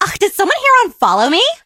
emz_start_vo_05.ogg